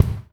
04A KICK  -L.wav